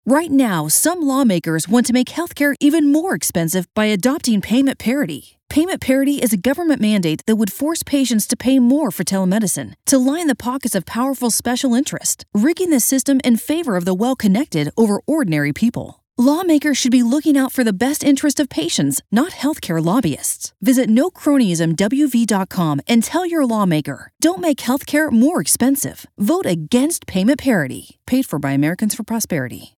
WV Payment Parity Radio Ad
WV-Payment-Parity-Radio-Ad.mp3